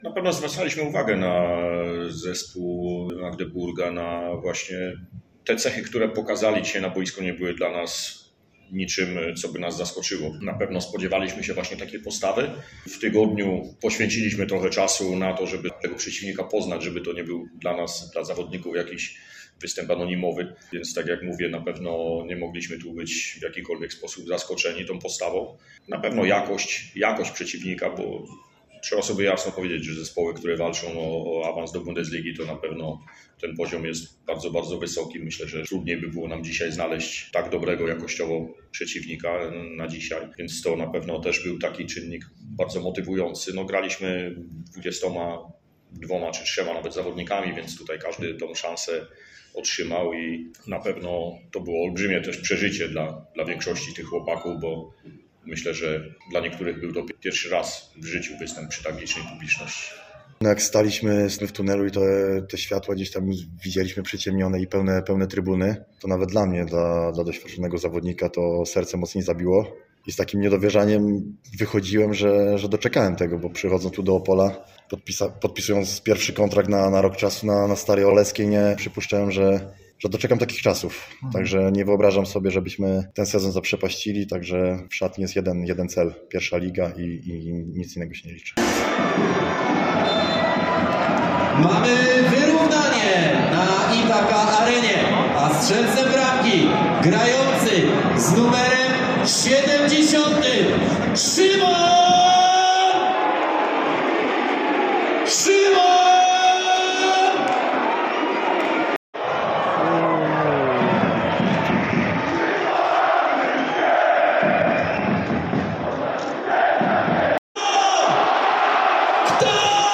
W poniższym dźwięku będzie można także usłyszeć doping  kibiców Odry Opole oraz FC Magdeburg, którzy niejednokrotnie rozgrywali pojedynek na przyśpiewki, mające na celu zmobilizowanie swoich graczy do ataku.
dzwiek-relacja.mp3